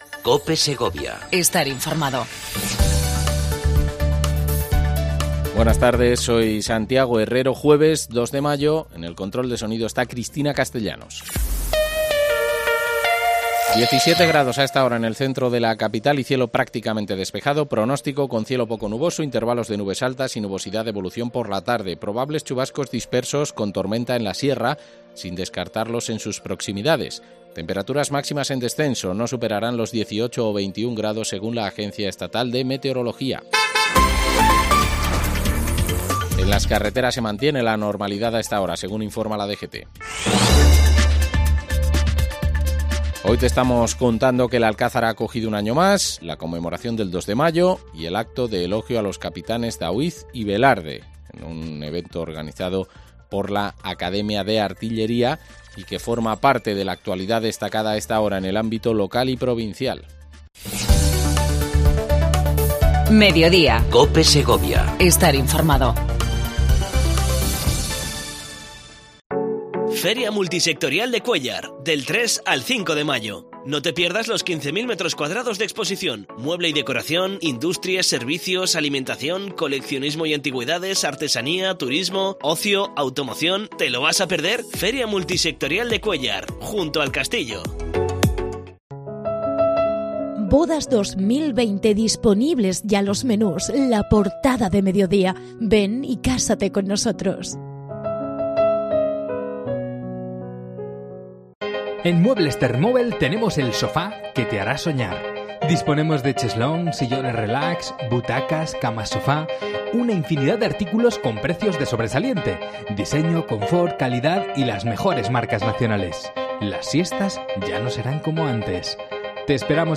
AUDIO: Entrevista a Nuria Fernández, Concejala de Industria, Comercio, Turismo y Juventud.